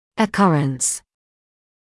[ə’kʌrəns][э’карэнс]частота, распространенность; случай, эпизод